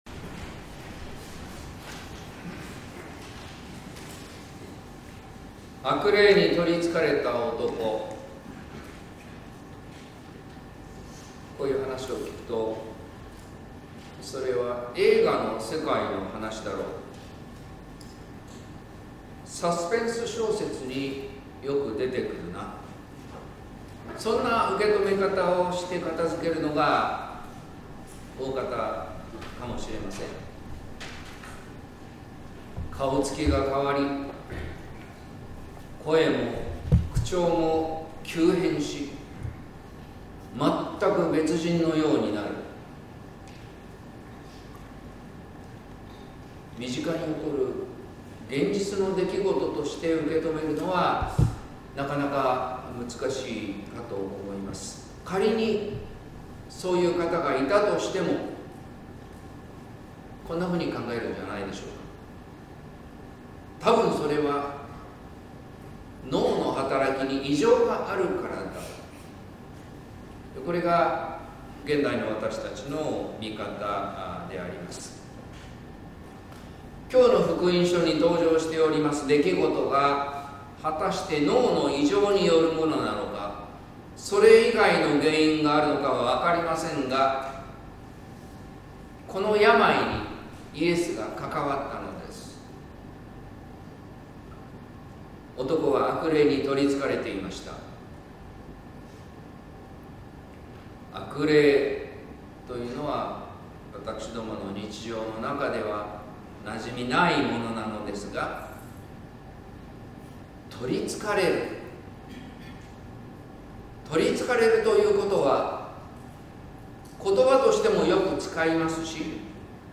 説教「キリストを着る」（音声版）